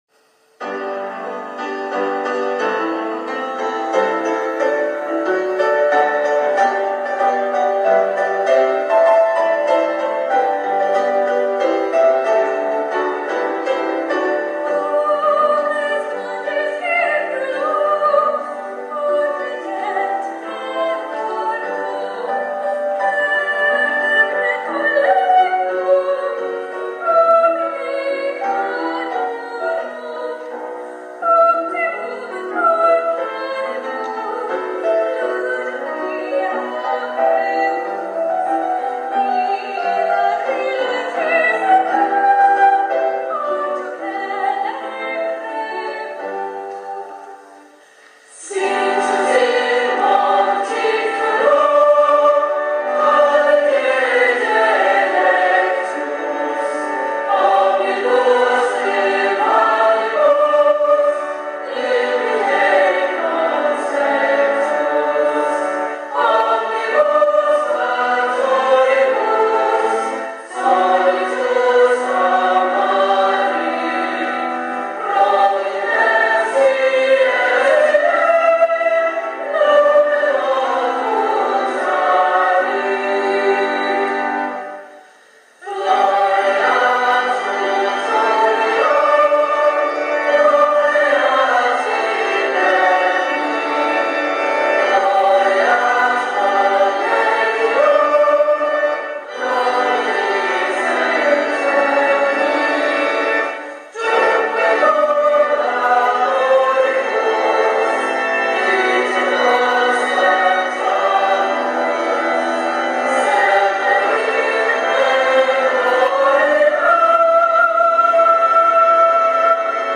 Latin words by Professor R.M. Henry; Music by F.H. Sawyer